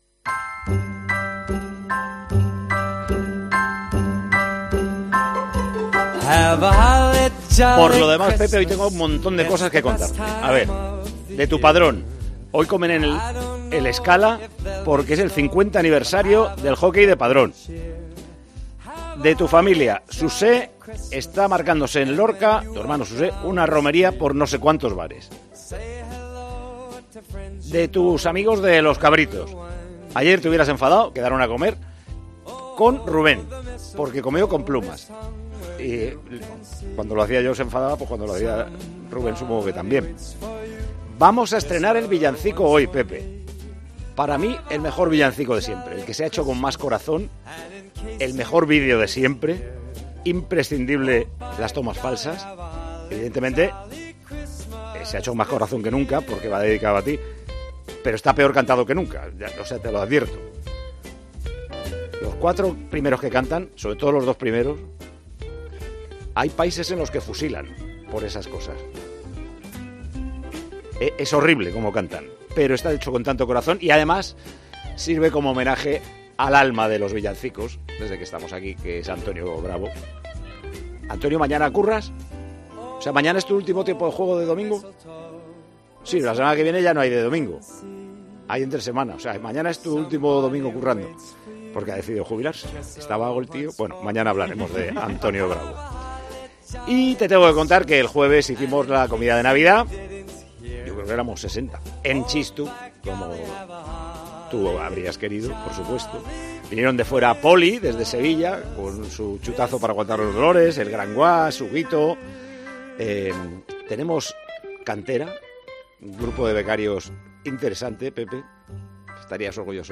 Como todos los sábados, Paco González ha abierto el programa de Tiempo de Juego recordando a Pepe Domingo Castaño y explicándole lo que había dado de sí la semana.